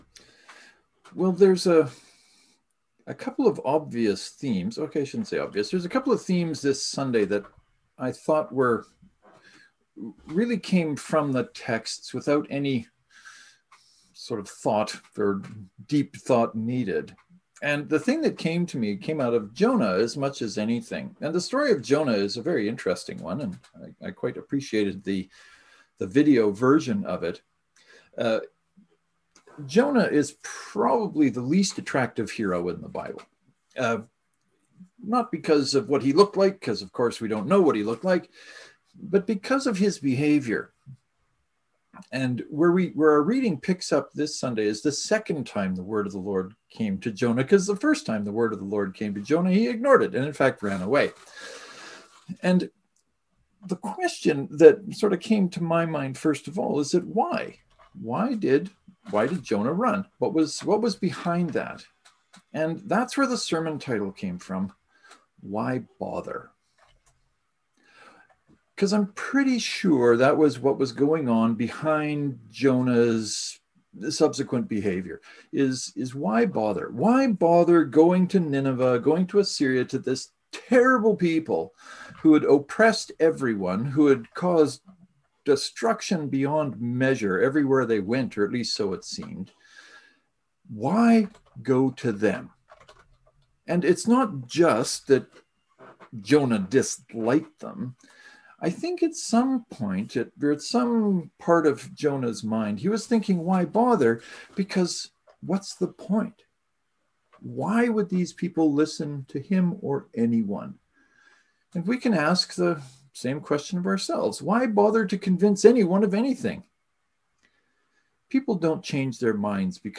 This Sunday’s sermon is an answer to why we should bother.